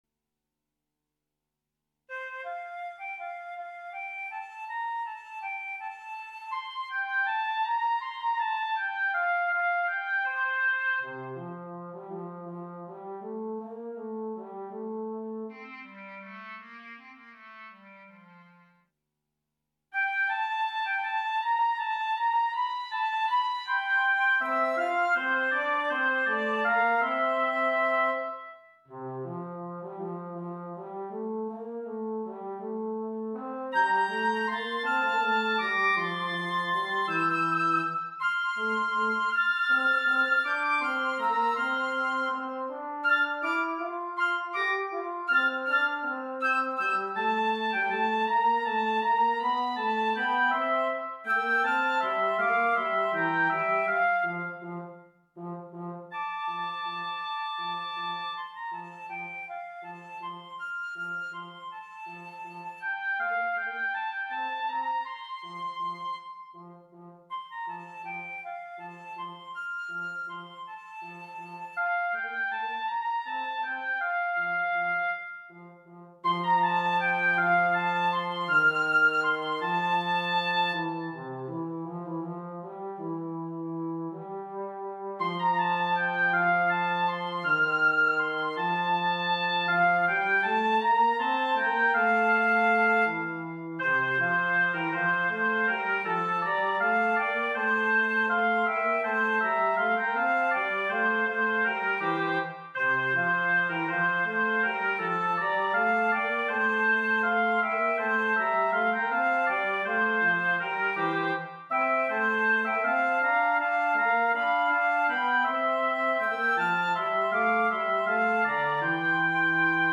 minus Instrument 4